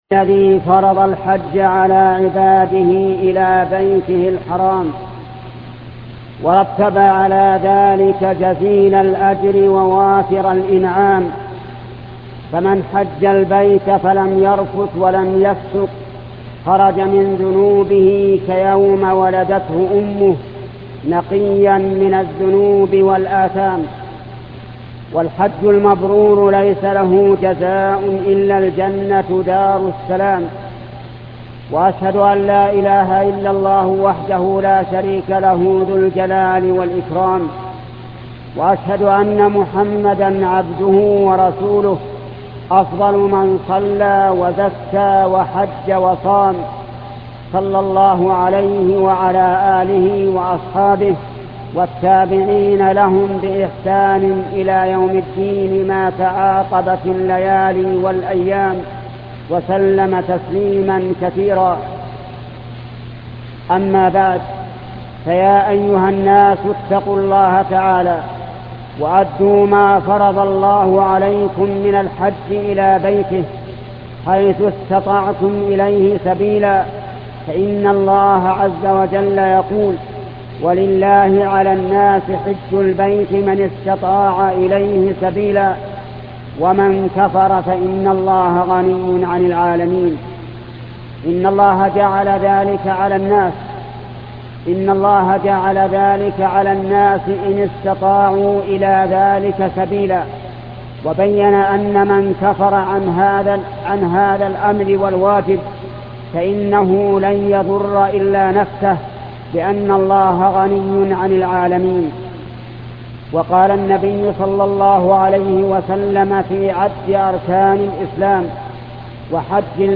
خطبة الحج الشيخ محمد بن صالح العثيمين